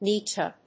エヌ‐シーティー‐エー